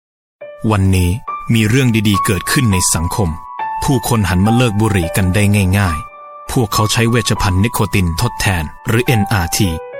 • 10泰语男声5号